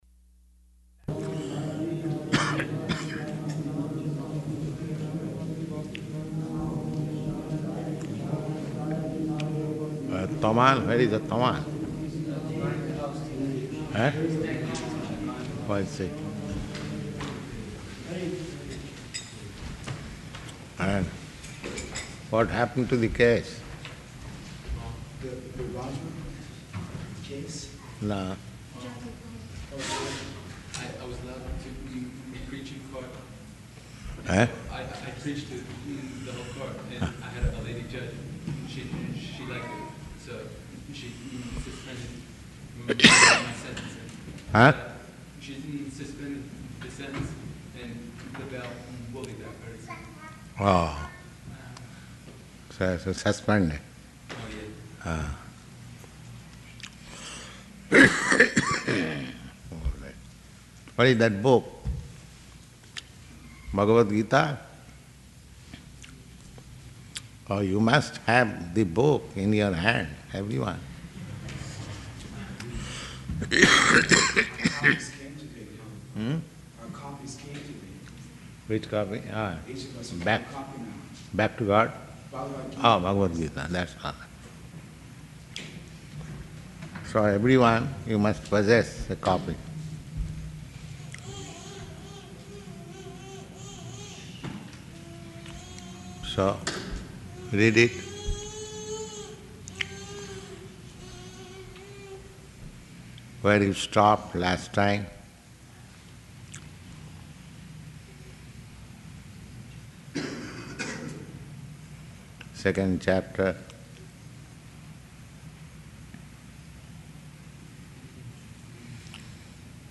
December 11th 1968 Location: Los Angeles Audio file